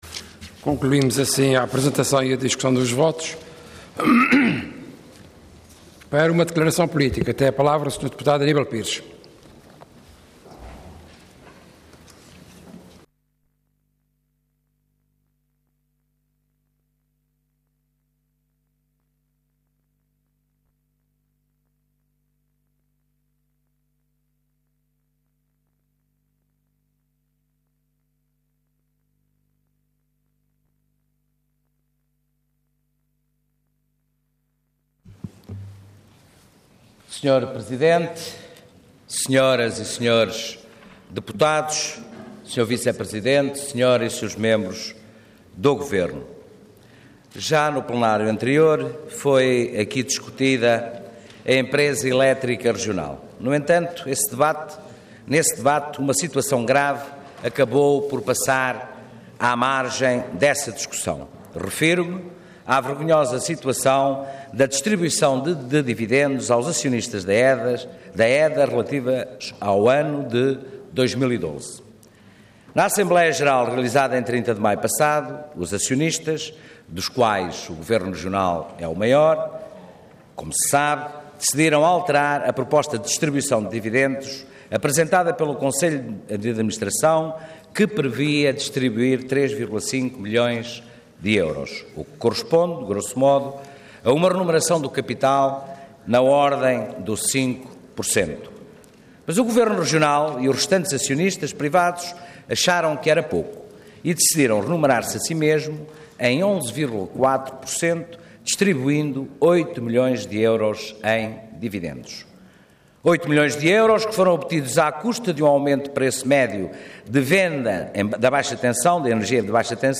Intervenção Declaração Política Orador Aníbal Pires Cargo Deputado Entidade PCP